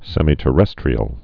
(sĕmē-tə-rĕstrē-əl, sĕmī-)